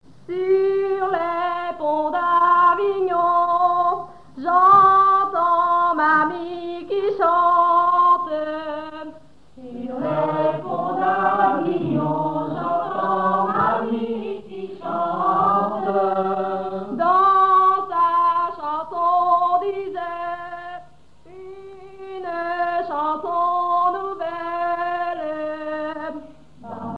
Mémoires et Patrimoines vivants - RaddO est une base de données d'archives iconographiques et sonores.
Genre laisse
chansons à danser ronds et demi-ronds
Catégorie Pièce musicale inédite